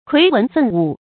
揆文奮武 注音： ㄎㄨㄟˊ ㄨㄣˊ ㄈㄣˋ ㄨˇ 讀音讀法： 意思解釋： 施行文教，振奮武事。